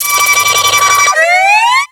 Cri de Mélokrik dans Pokémon X et Y.